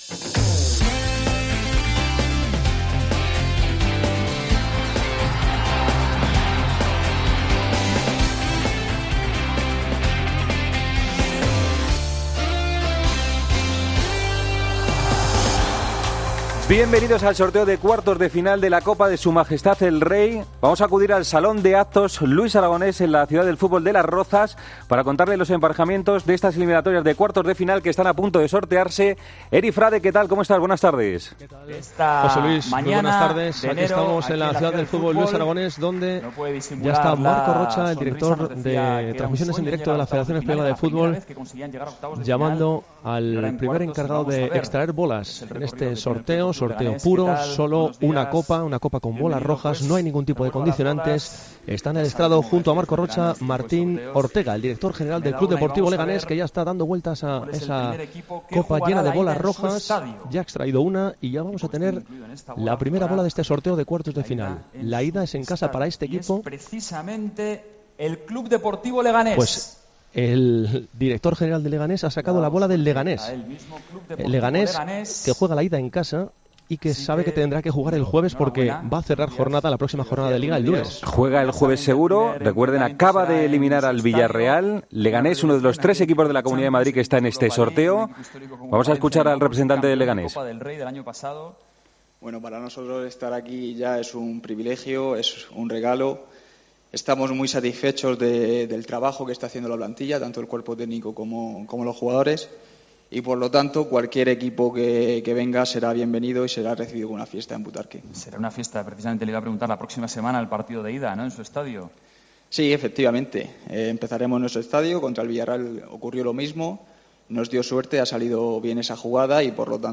Escucha la retransmisión del sorteo de cuartos de final de la Copa del Rey